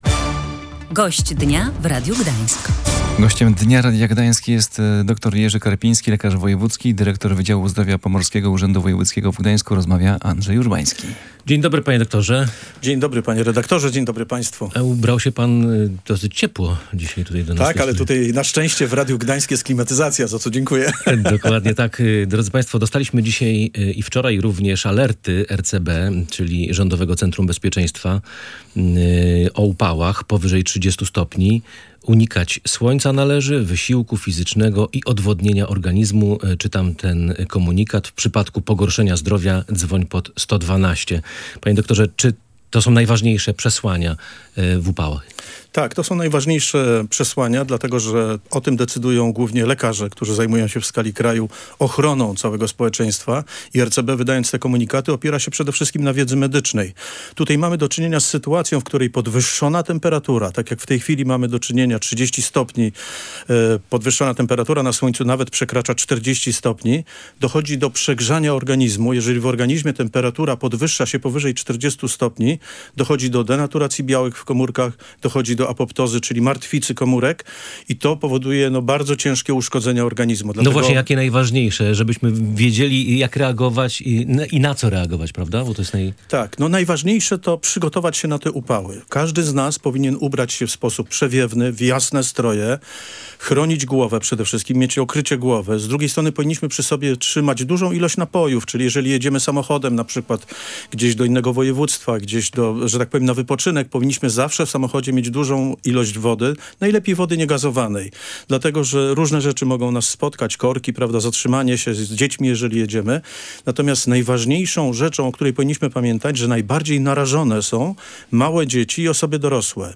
W audycji „Gość Dnia Radia Gdańsk” Jerzy Karpiński, pomorski lekarz wojewódzki, dyrektor Wydziału Zdrowia Pomorskiego Urzędu Wojewódzkiego w Gdańsku radził, co należy zrobić, żeby uniknąć porażenia słonecznego, a kiedy bezwzględnie należy wezwać pogotowie.